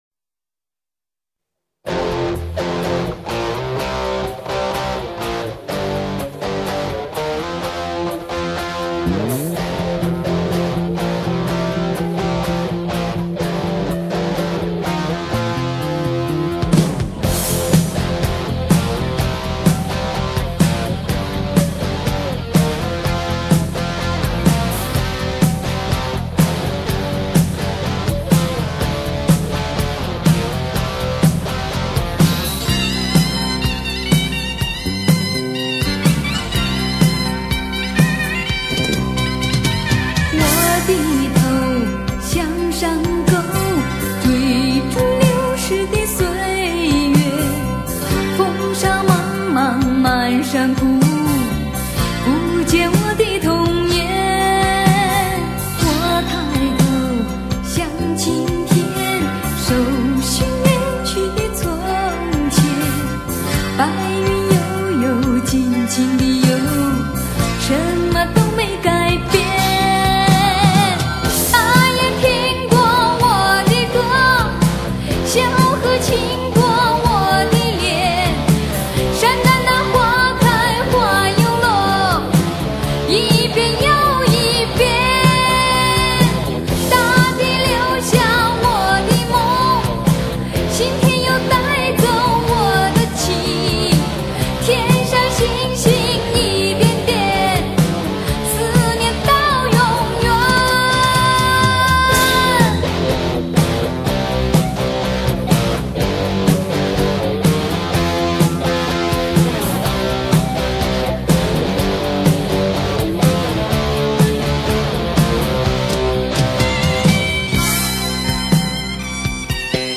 以其清新纯美的演唱风格奠定了她在当时中国歌坛的领先地位
开创了新民歌“西北风”的先河